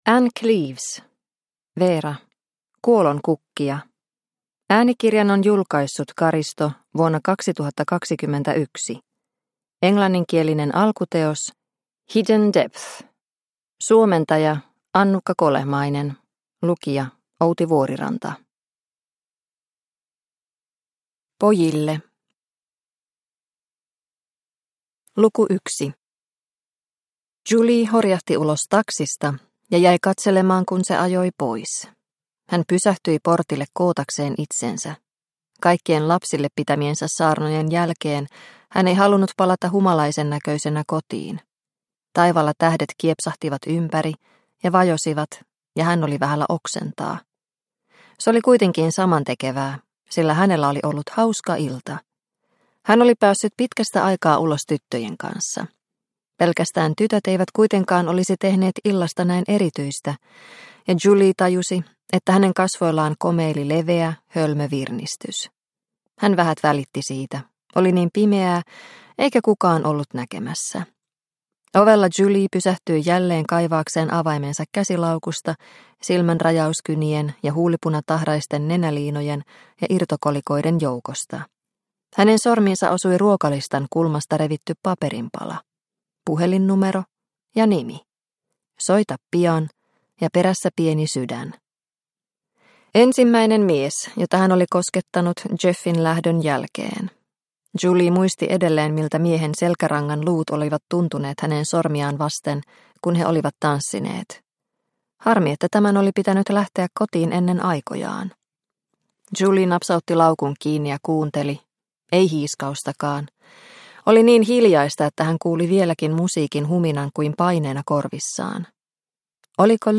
Kuolonkukkia – Ljudbok – Laddas ner